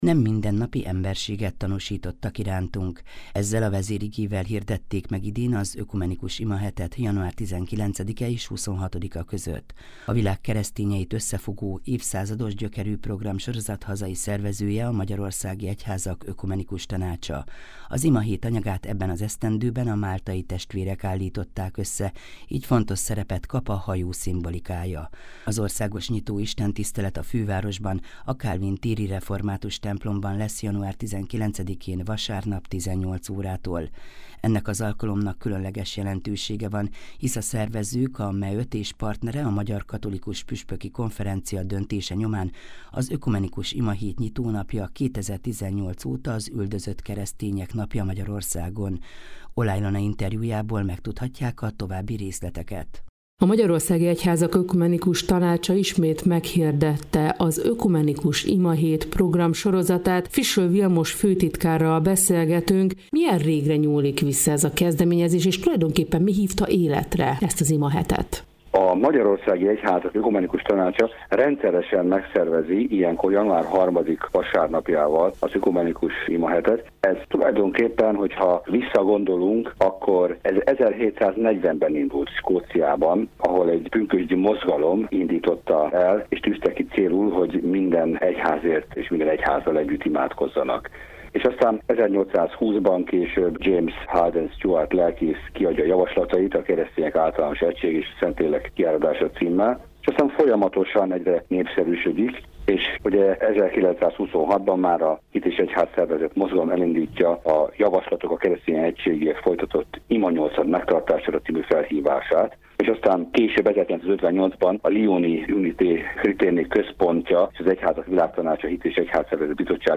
interjút az Európa Rádióban